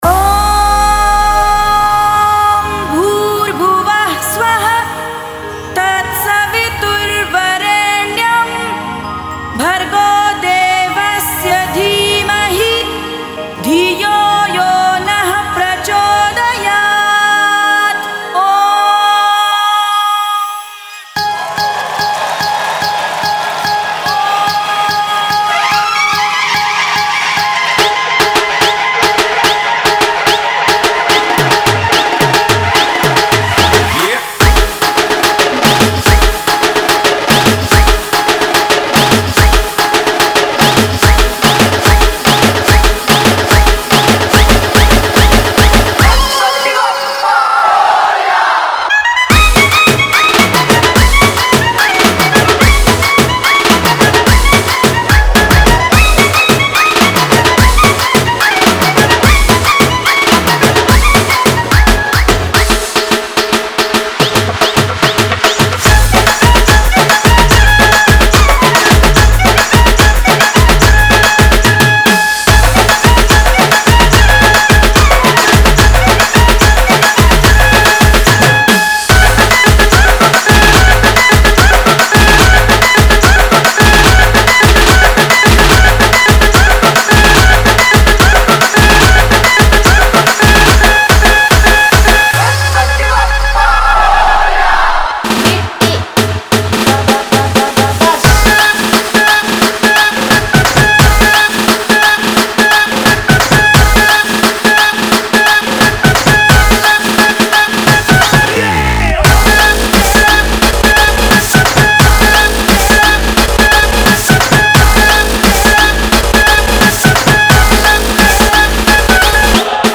Bhajan Dj Song Collection 2021